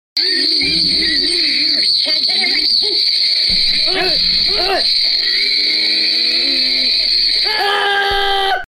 Funny sound effects free download